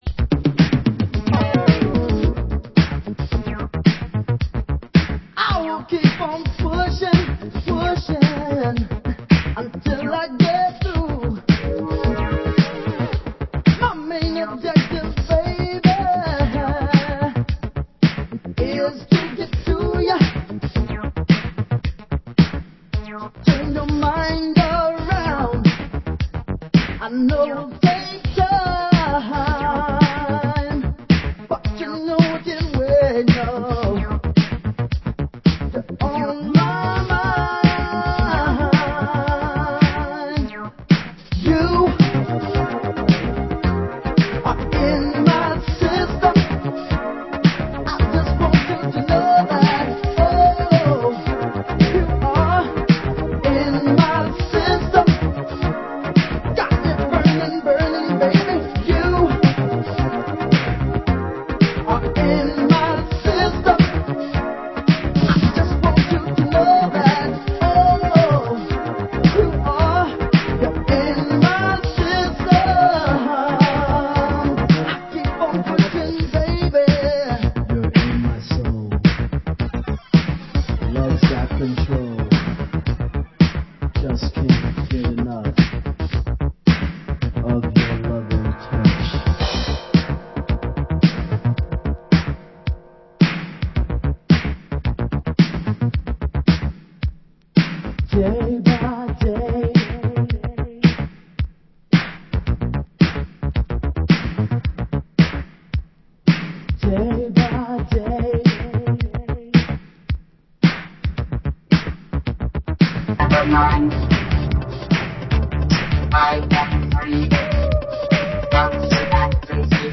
Genre: Disco